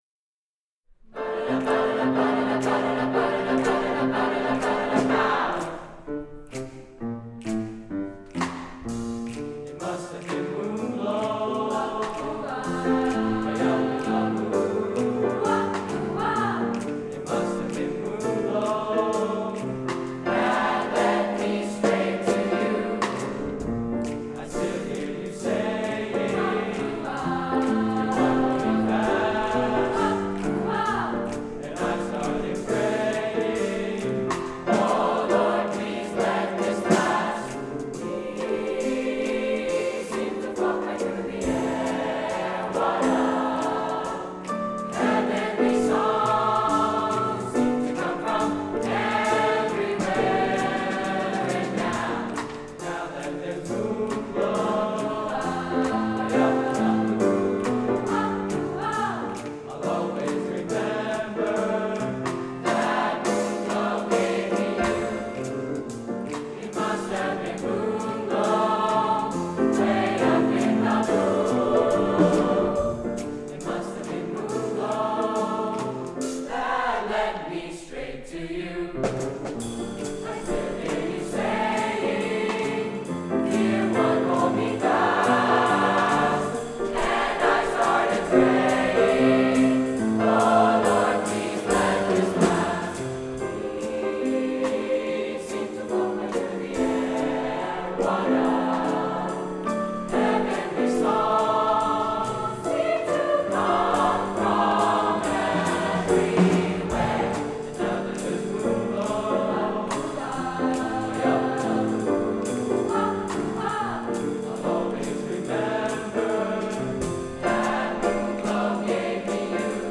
Brookline High School Spring Music Festival
Concert Choir